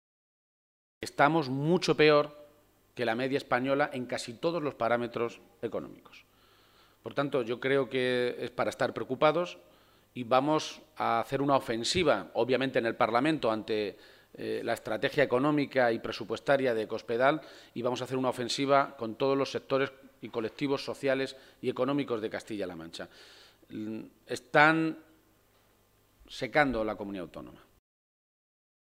Page-Bellido en rueda de prensa